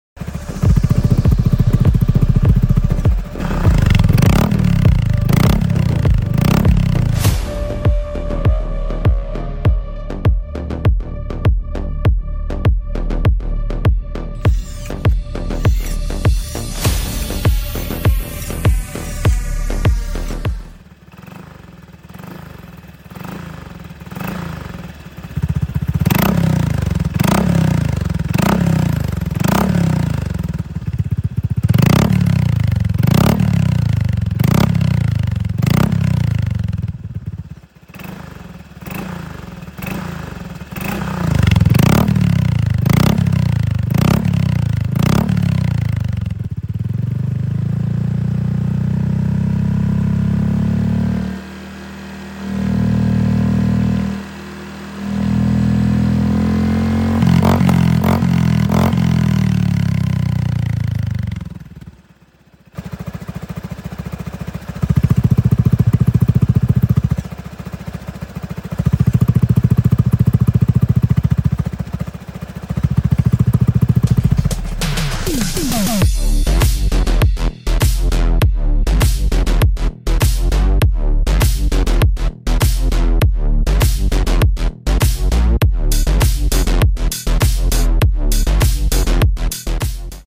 Tridente Cerberus Pcx150 We Sound Effects Free Download